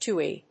/tiːw(米国英語)/